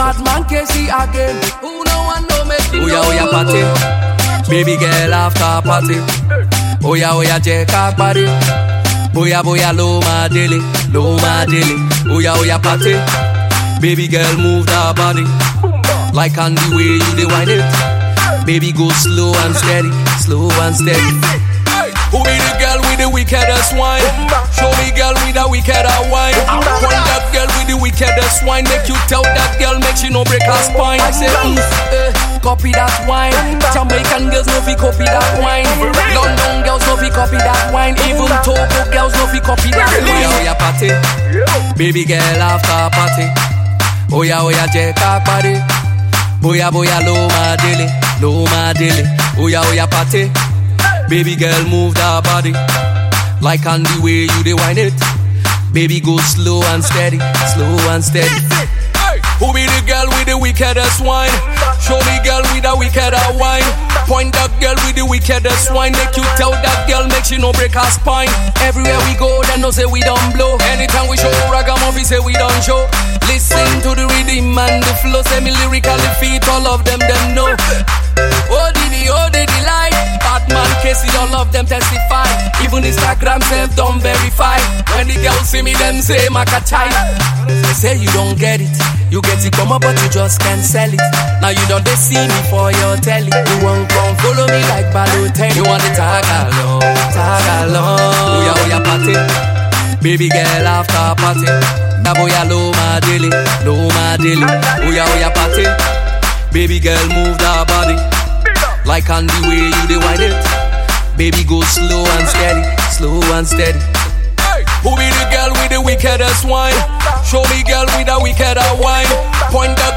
fast tempo smash jam that will make you dance.